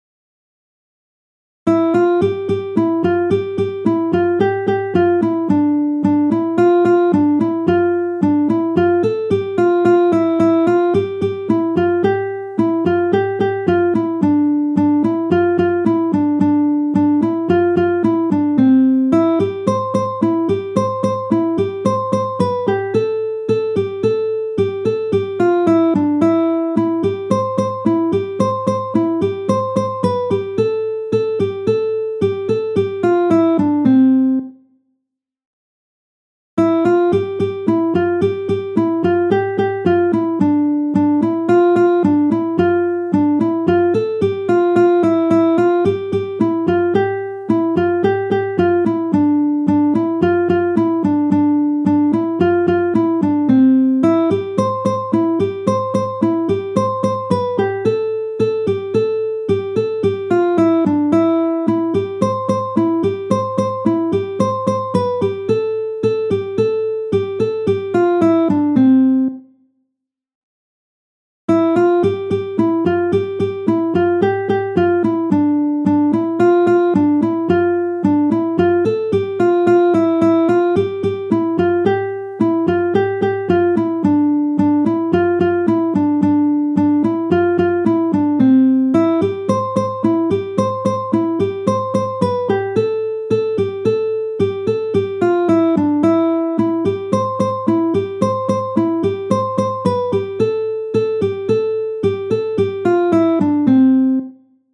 VERSION INSTRUMENTALA
VERSION INSTRUMENTALE